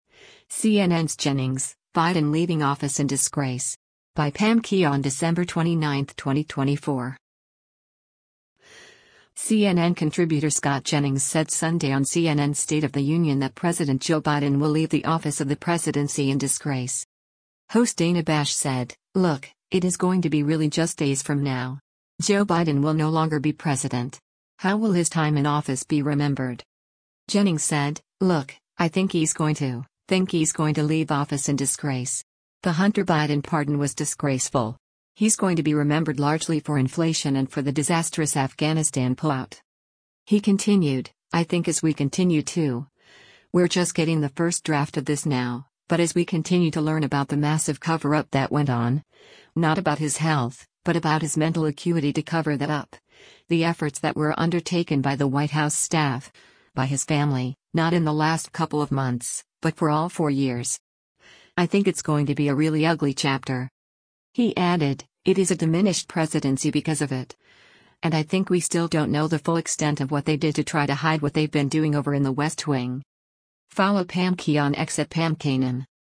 CNN contributor Scott Jennings said Sunday on CNN’s “State of the Union” that President Joe Biden will leave the office of the presidency in “disgrace.”